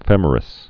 (fĕmər-ĭs)